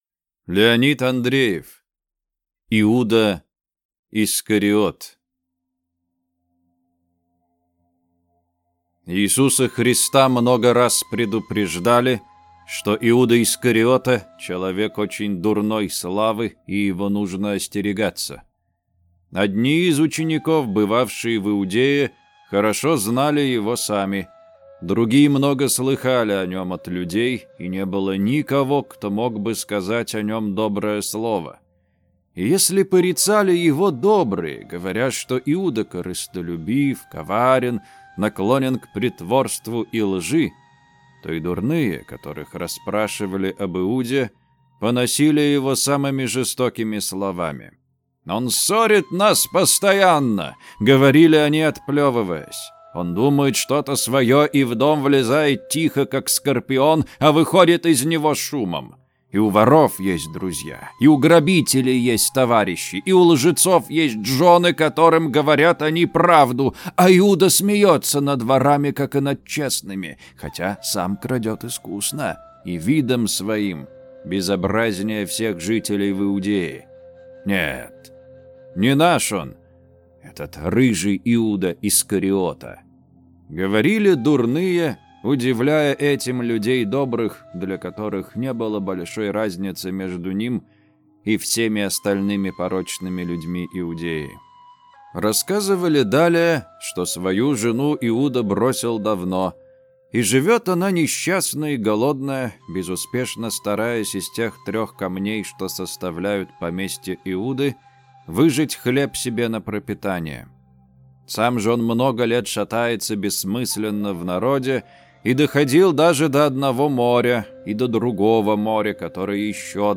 Аудиокнига Иуда Искариот | Библиотека аудиокниг
Прослушать и бесплатно скачать фрагмент аудиокниги